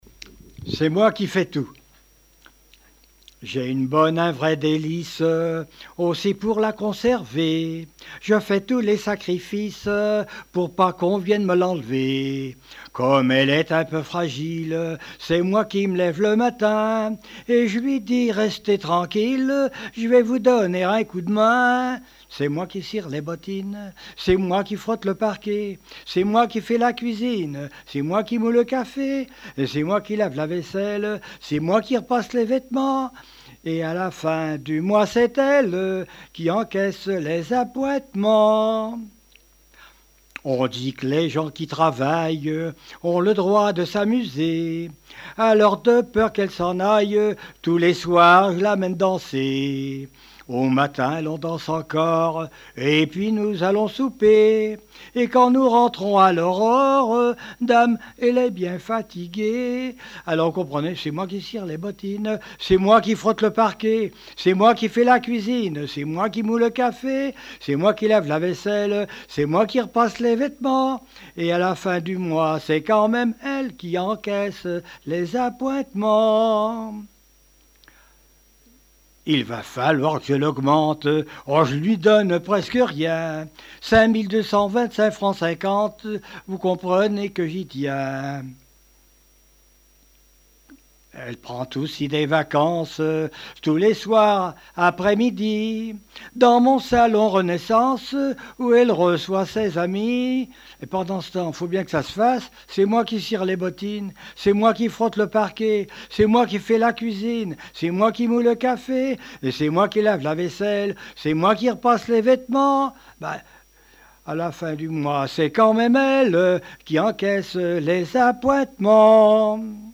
Genre strophique
Enquête Arexcpo en Vendée
Pièce musicale inédite